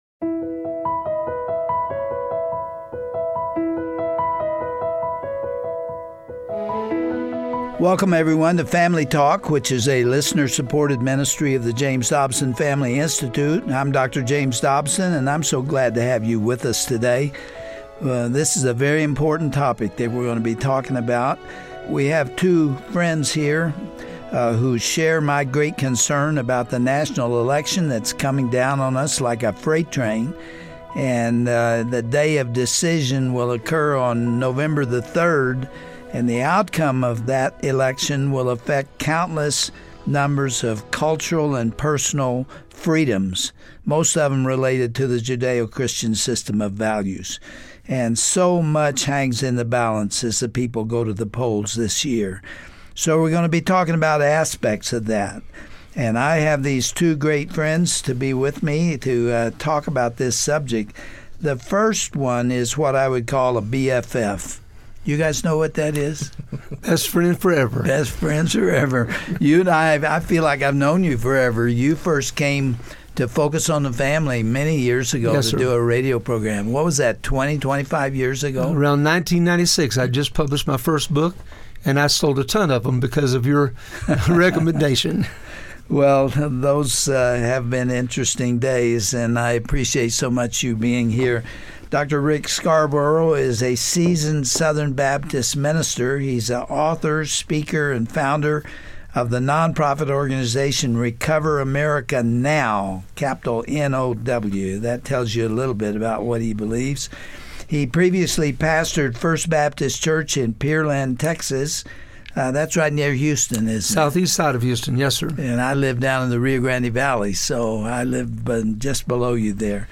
Dr. Dobson is joined by two representatives from a new voter mobilization effort called the Jonathan Project.